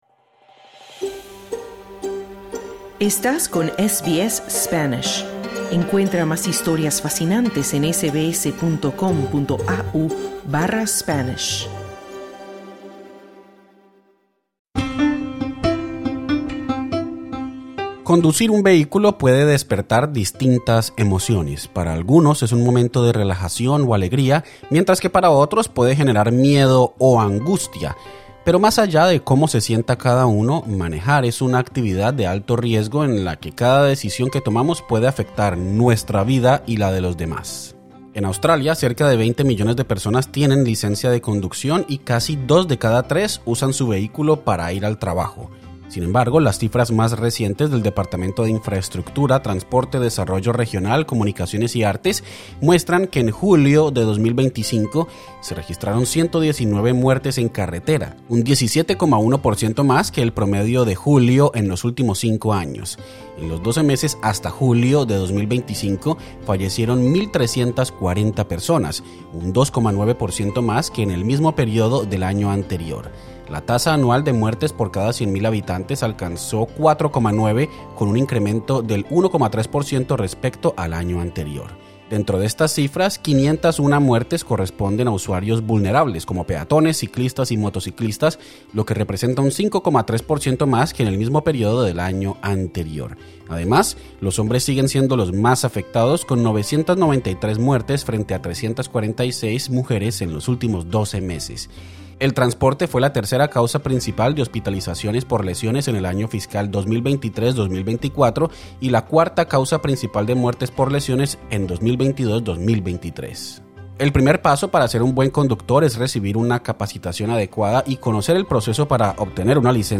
Hablamos con un instructor de conducción certificado en Victoria, quien explica las claves para ser un conductor seguro y el proceso para obtener la licencia.